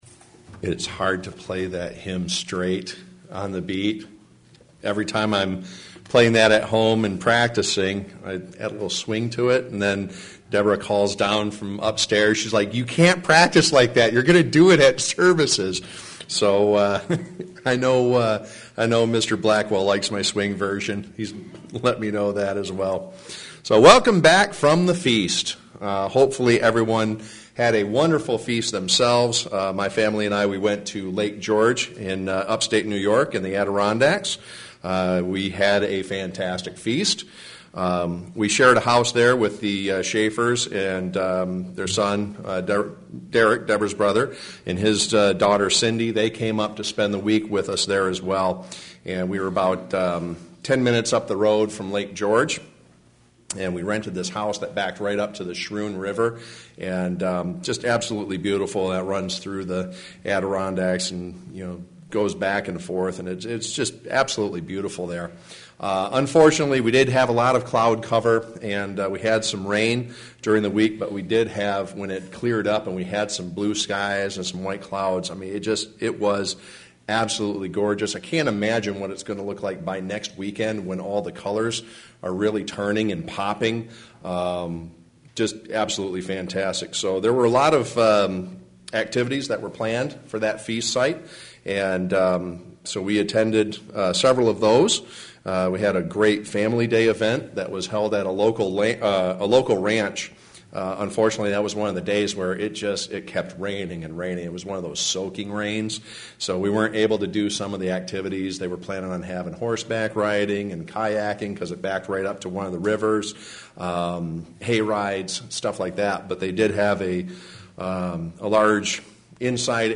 Sermons
Given in Flint, MI